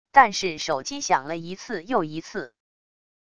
但是手机响了一次又一次wav音频生成系统WAV Audio Player